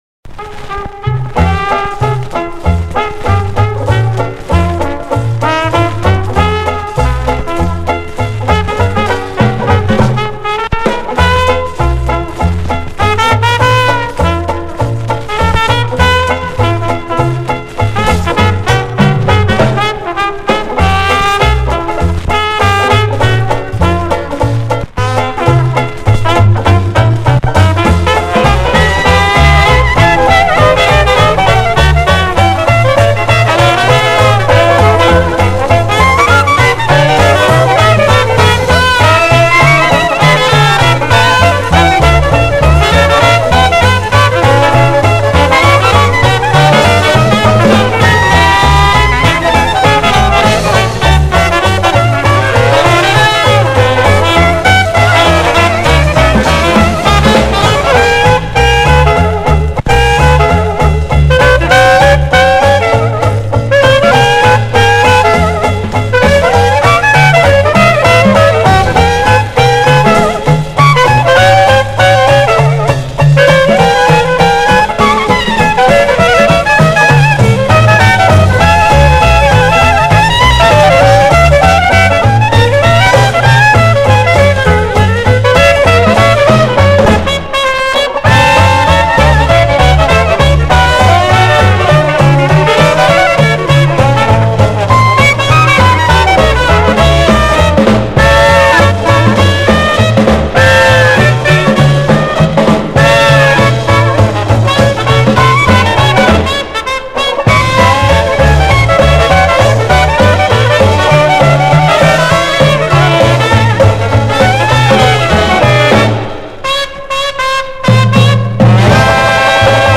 The Beats liked Traditional Jazz.